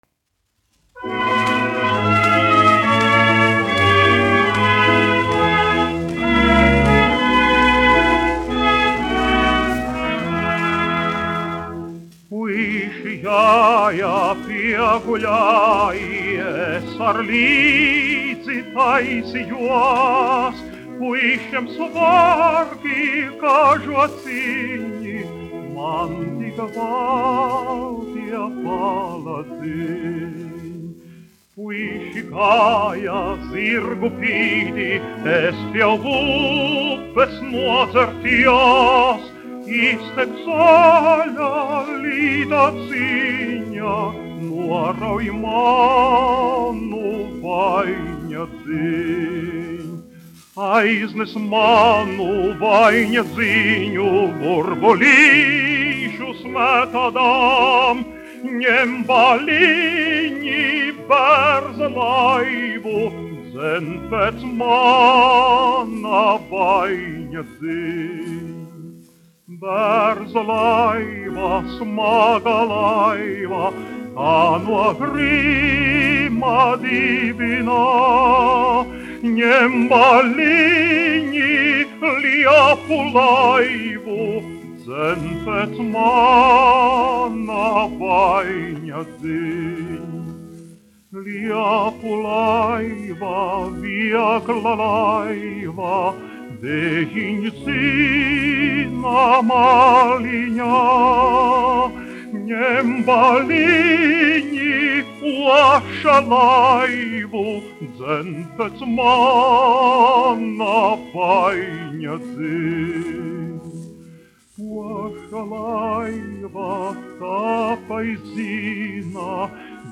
Puiši jāja pieguļā : tautas dziesma
A. Pļiševskis, aranžētājs
1 skpl. : analogs, 78 apgr/min, mono ; 25 cm
Latviešu tautasdziesmas
Latvijas vēsturiskie šellaka skaņuplašu ieraksti (Kolekcija)